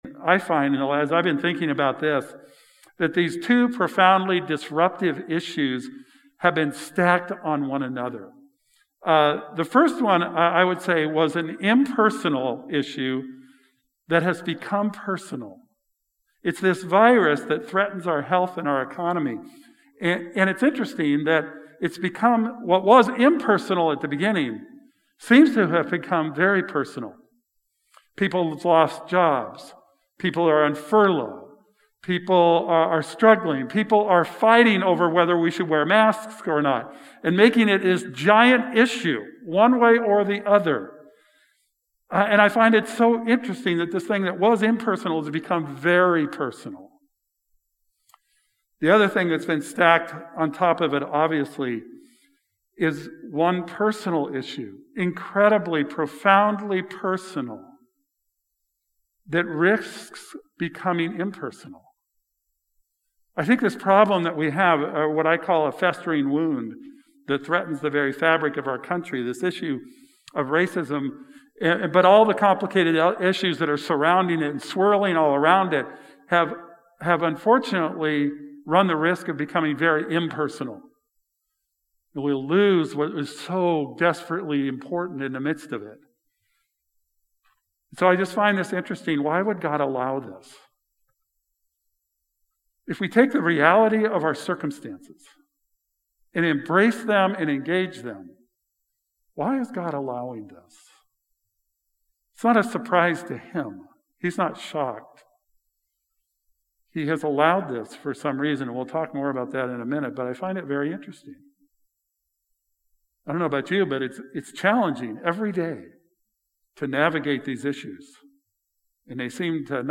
Sunday Service: June 28, 2020